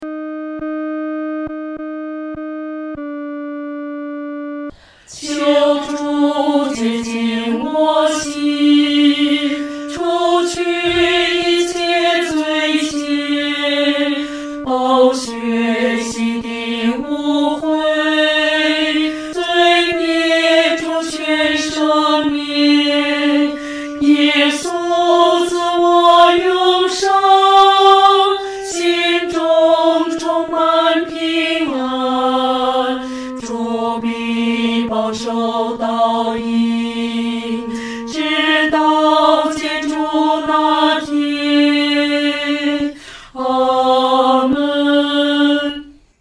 合唱
女低
诗班在二次创作这首诗歌的时候，要清楚这首诗歌的音乐表情是虔诚、深情地。